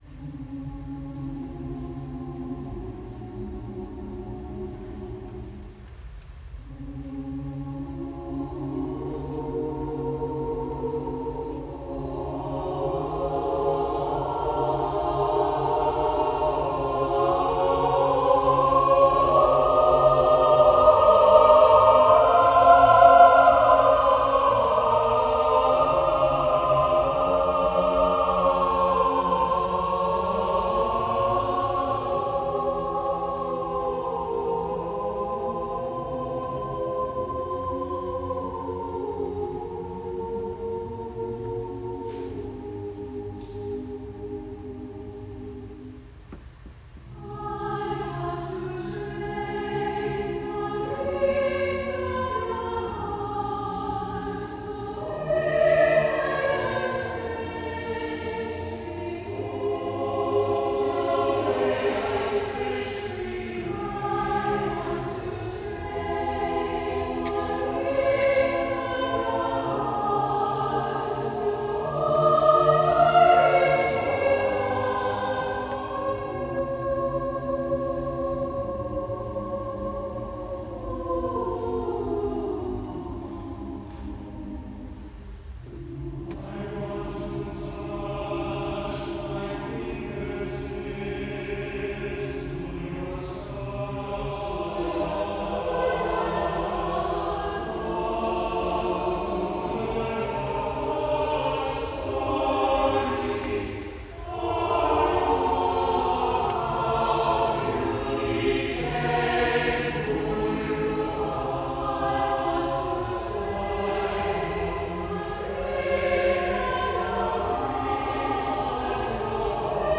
Voicing: SATB divisi
Instrumentation: a cappella